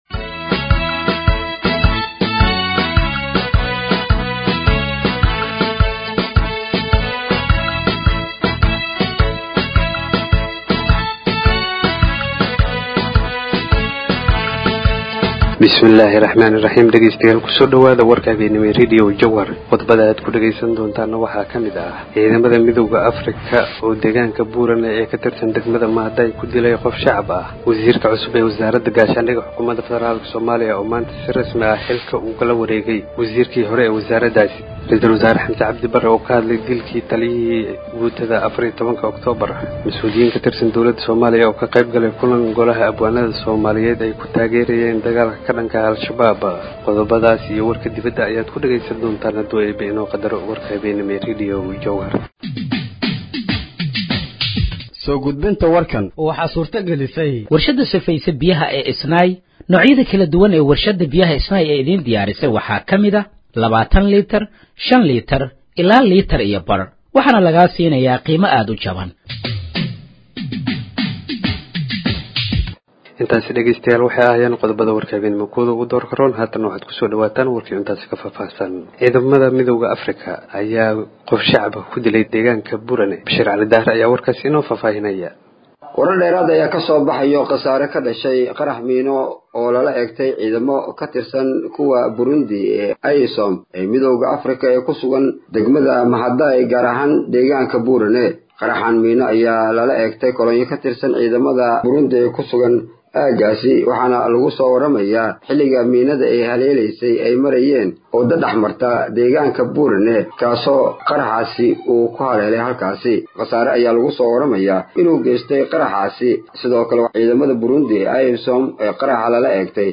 Dhageeyso Warka Habeenimo ee Radiojowhar 06/04/2025
Halkaan Hoose ka Dhageeyso Warka Habeenimo ee Radiojowhar